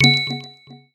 HTC Desire Bildirim Sesleri